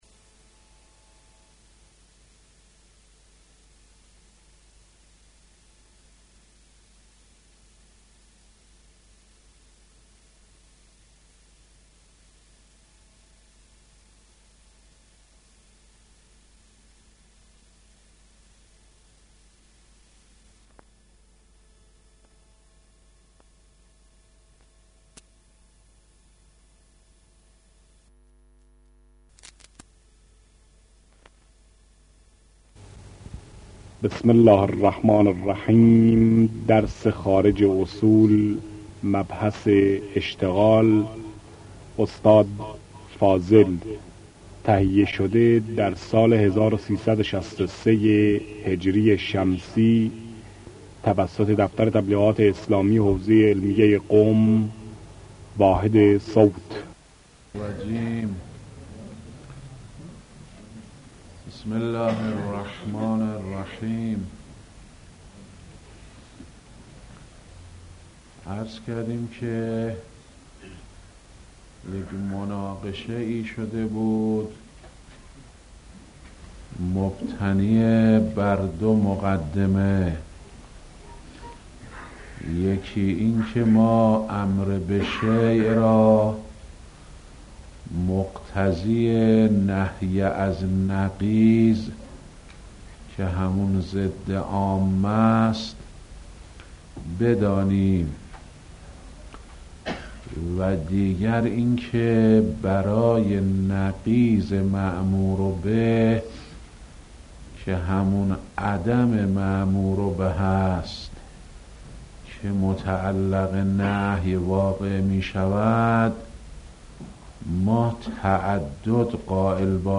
آيت الله فاضل لنکراني - خارج اصول | مرجع دانلود دروس صوتی حوزه علمیه دفتر تبلیغات اسلامی قم- بیان